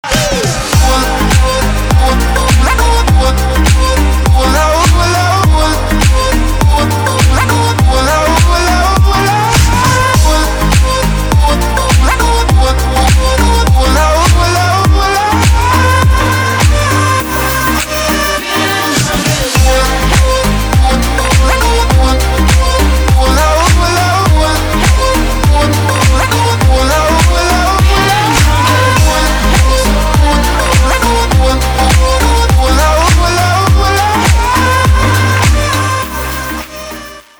• Качество: 320, Stereo
веселые
dance
EDM